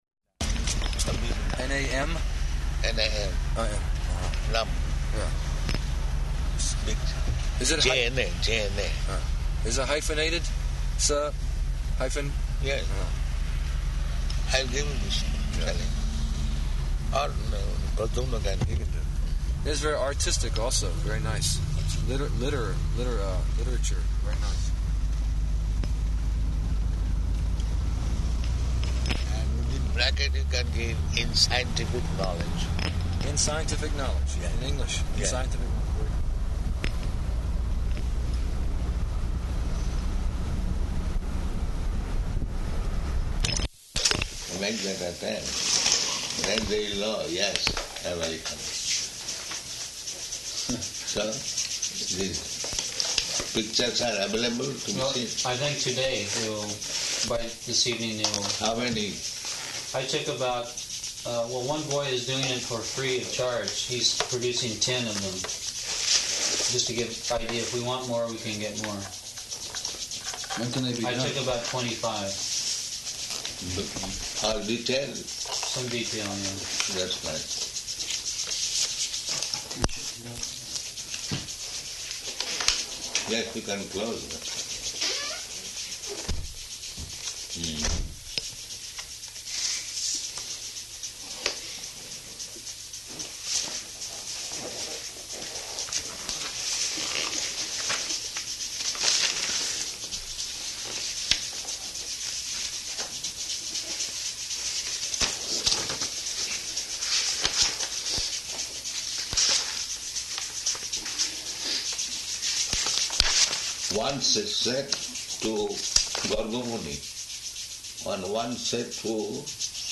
Room Conversation With Scientists
-- Type: Conversation Dated: July 6th 1976 Location: Washington, D.C. Audio file